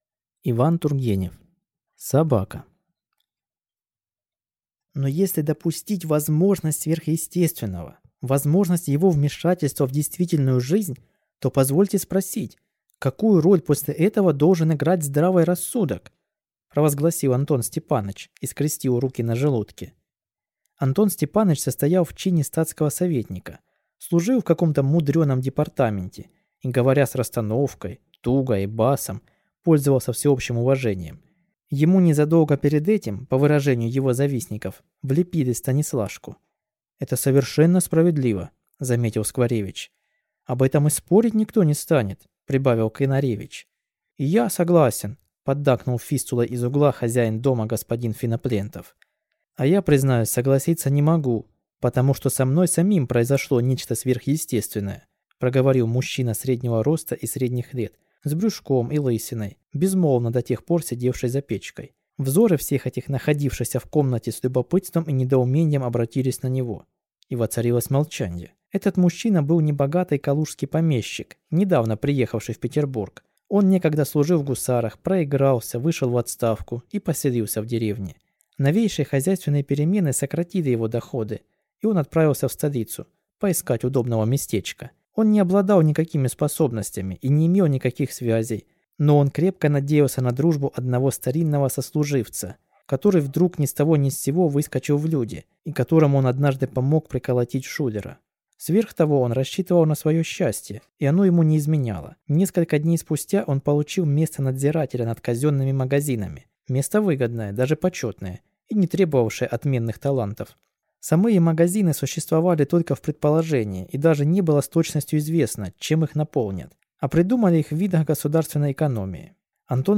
Аудиокнига Собака | Библиотека аудиокниг